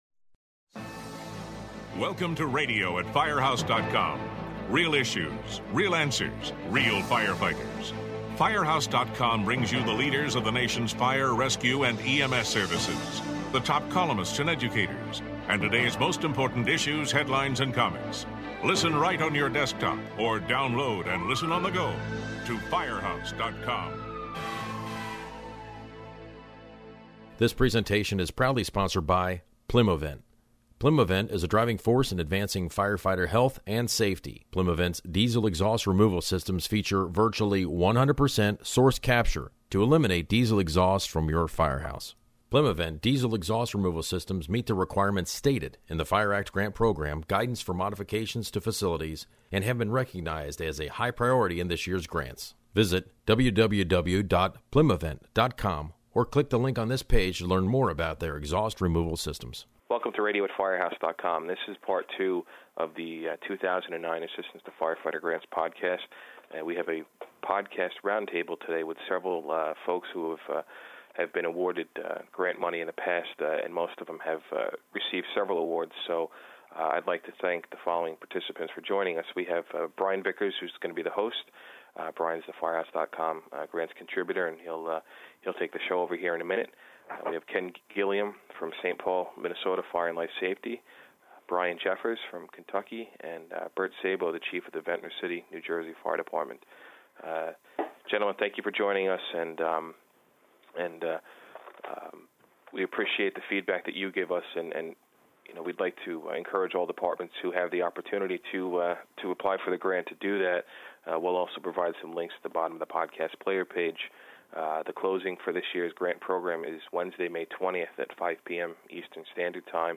roundtable chat with several people who have been successful at obtaining Assistance to Firefighters (AFG) program awards in the past.